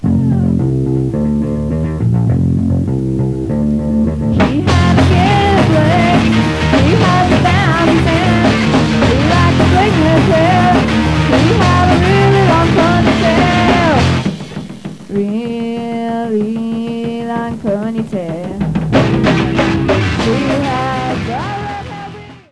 Vocals, Guitar, 12-String Guitar, Bass